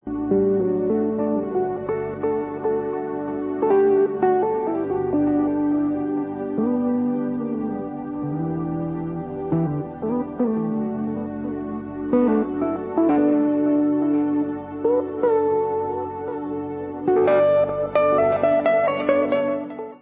Album je zhuštěným audio záznamem jarního turné skupiny